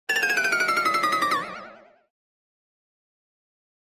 Comedy Piano Chromatic Down 5 - Fast Descent